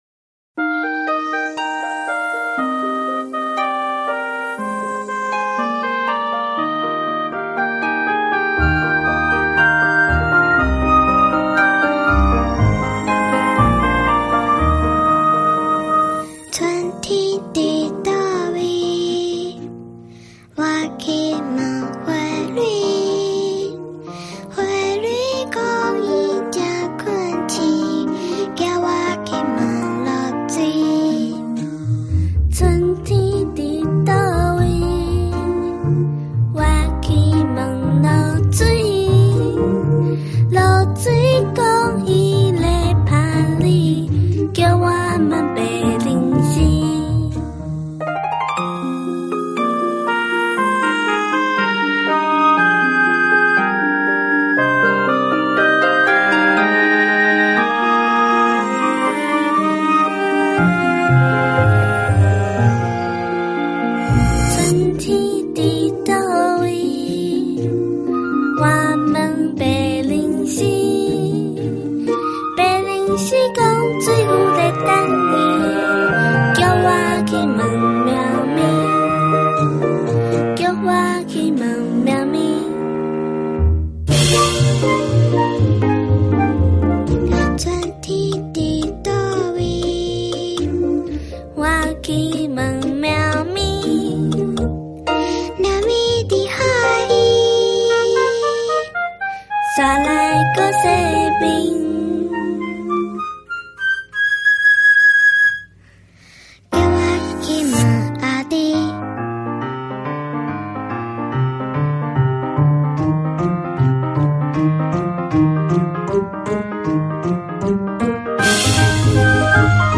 充满大地情怀的心灵之歌，唱出声音的美丽花朵
15首人文音乐，让耳朵与心灵重新相遇